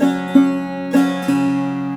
SAROD2    -L.wav